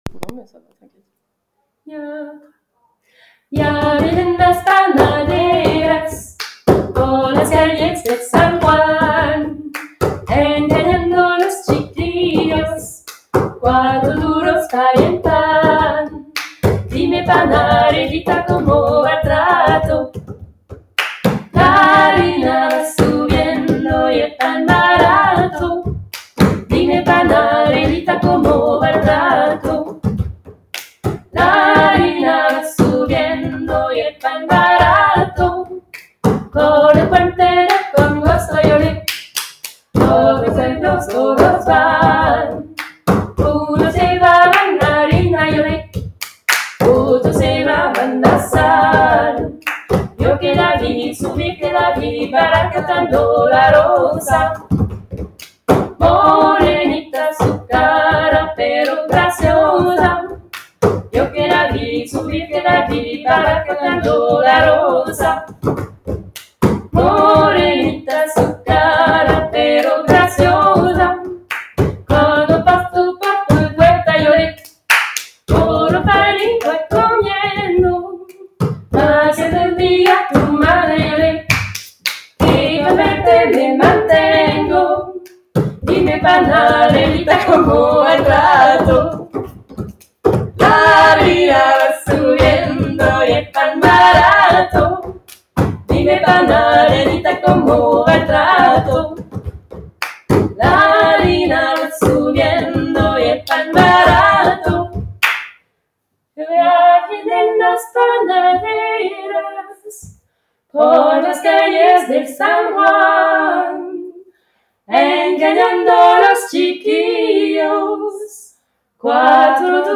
Retrouver nos chants, nos enregistrements, pour chanter entre femmes à Gap